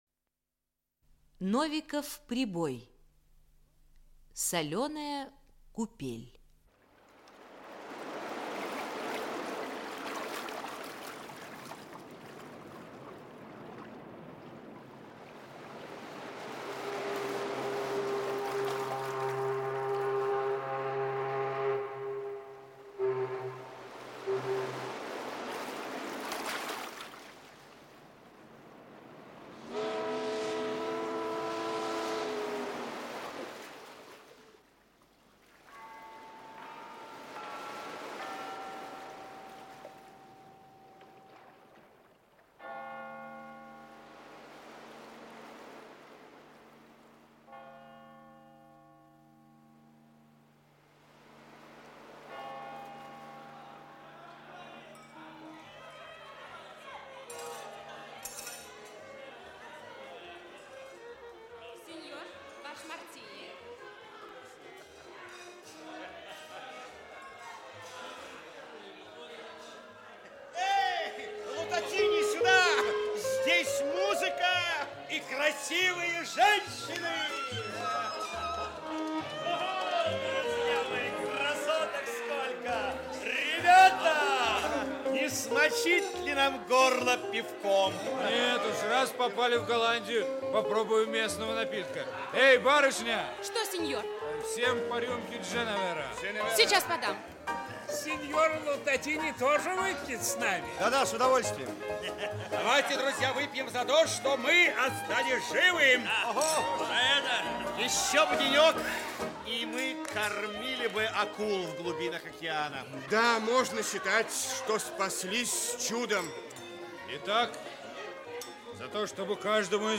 Аудиокнига Соленая купель | Библиотека аудиокниг
Aудиокнига Соленая купель Автор Алексей Новиков-Прибой Читает аудиокнигу Актерский коллектив.